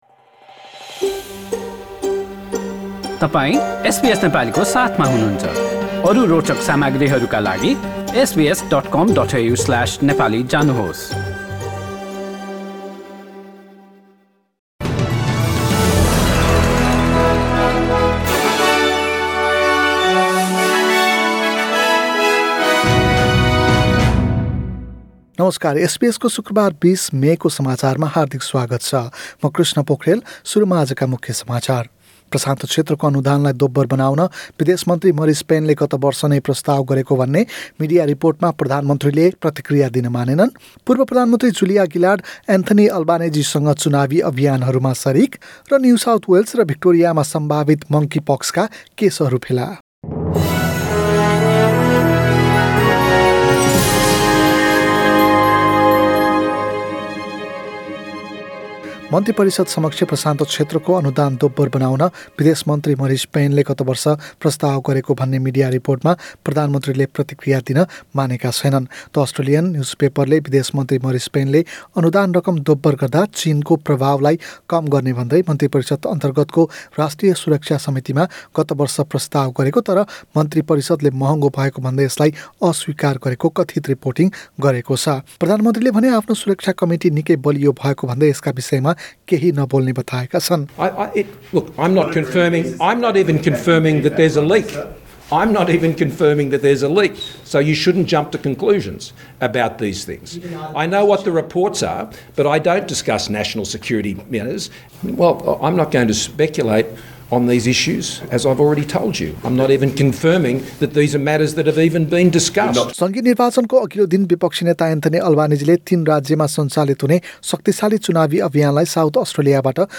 एसबीएस नेपाली अस्ट्रेलिया समाचार: शुक्रबार २० मे २०२२